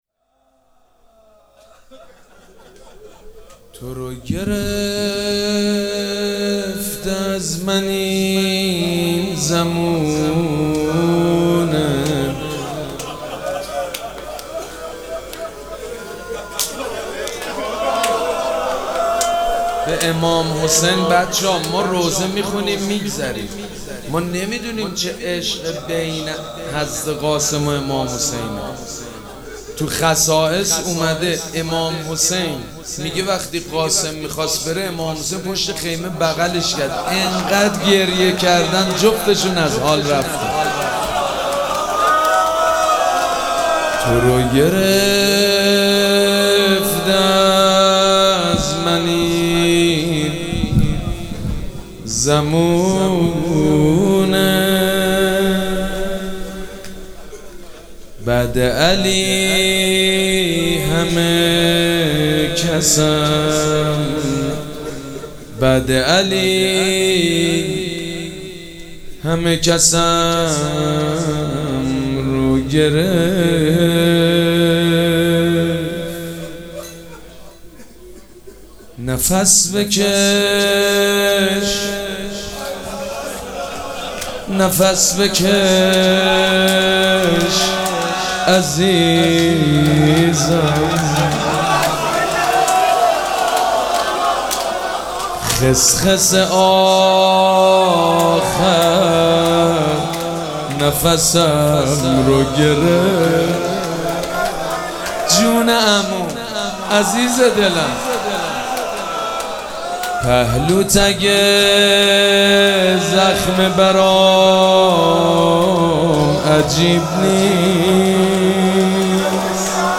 مراسم عزاداری شب ششم محرم الحرام ۱۴۴۷
روضه
حاج سید مجید بنی فاطمه